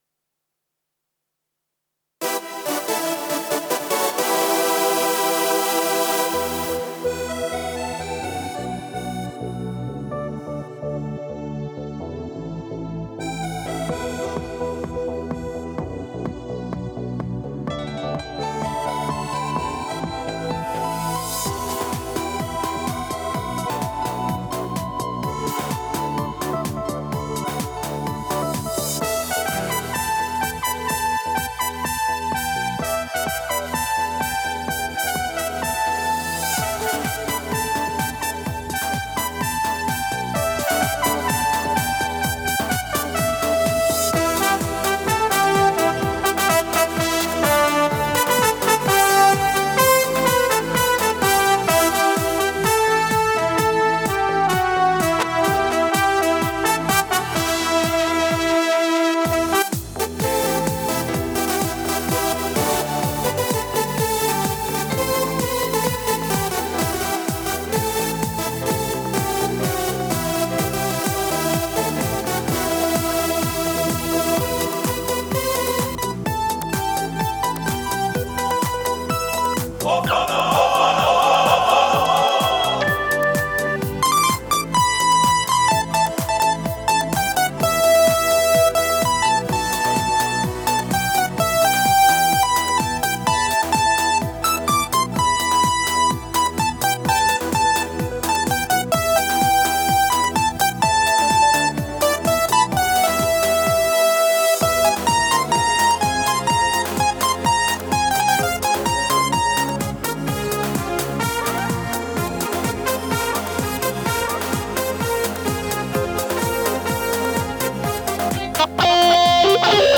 ניסיון בסמפלר של קורג